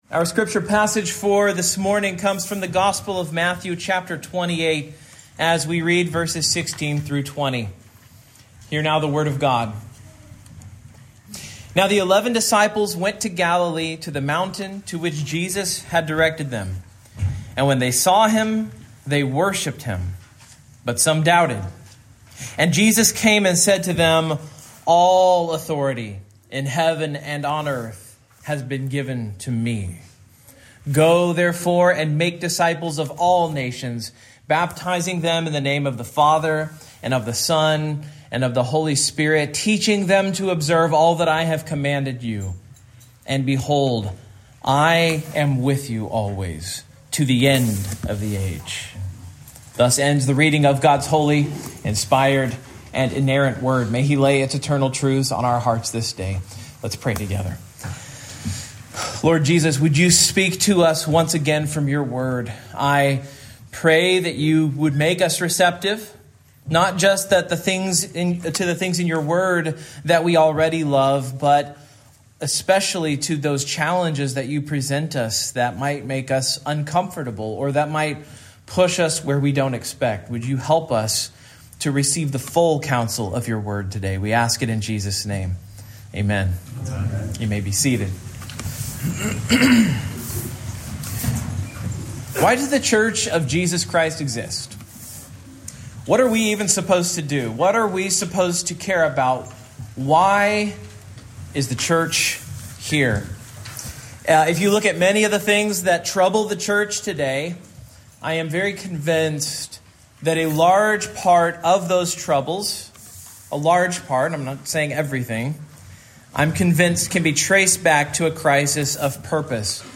Passage: Matthew 28:16-20 Service Type: Morning